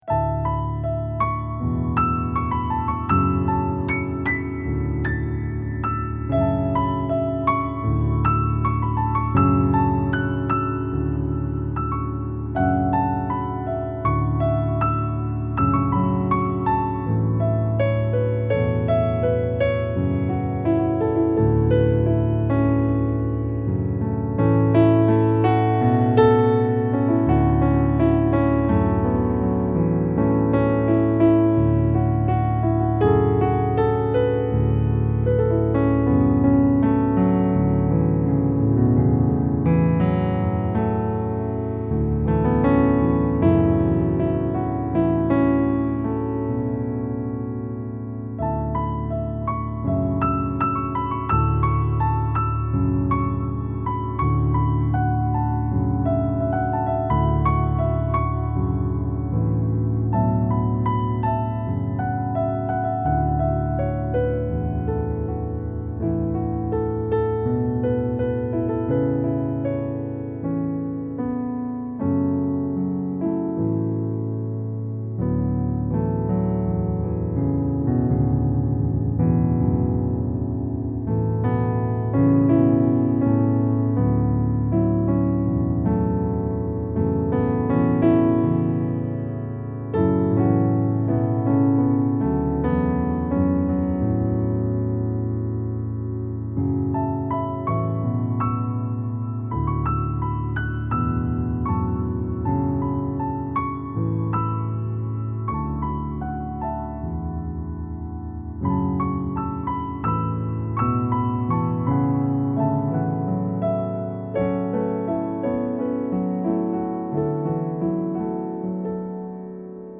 Improvisations
The results are much less polished than the songs above, but I still like them.
unscheduled wistfulness.mp3